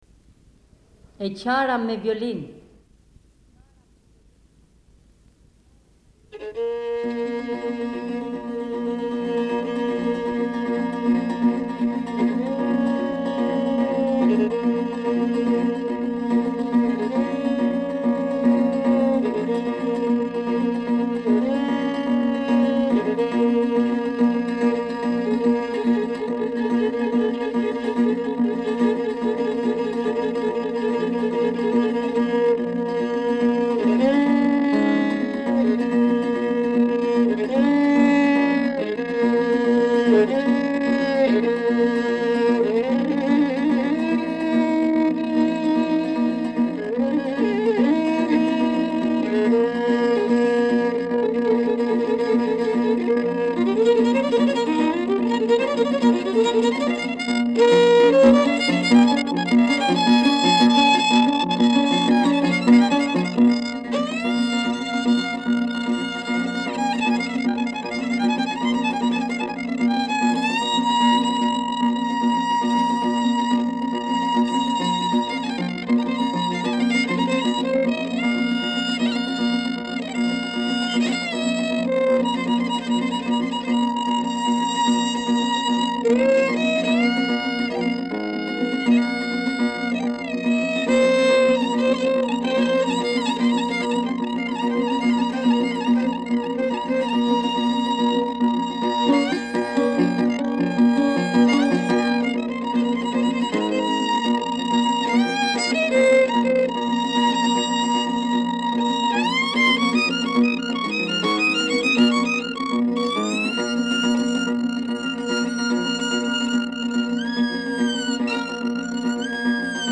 E qara me violine